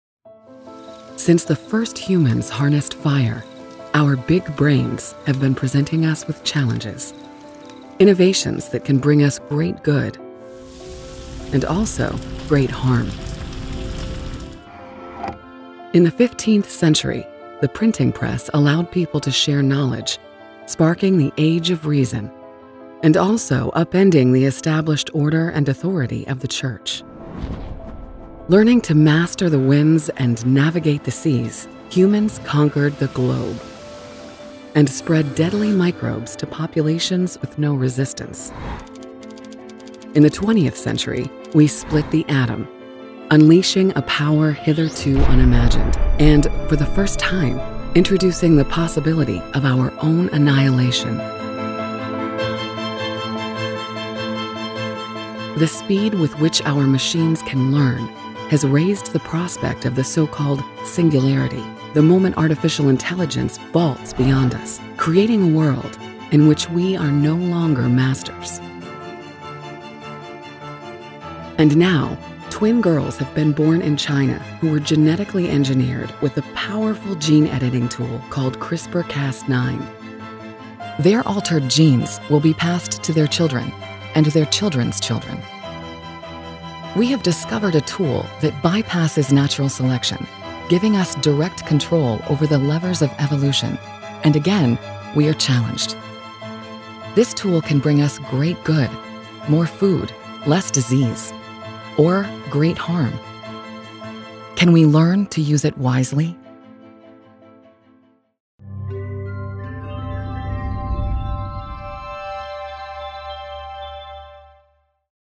Demo
Adult
Has Own Studio
mid atlantic
southern us
standard us
authoritative
friendly
husky
smooth
warm
well spoken